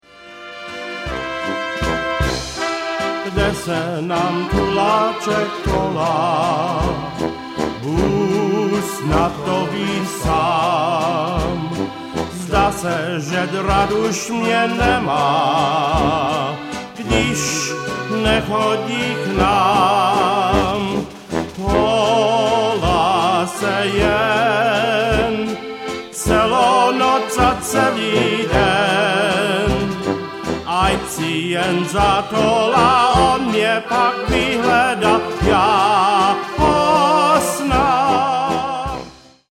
Czech Music in Wisconsin
piano
trumpet
tuba
accordion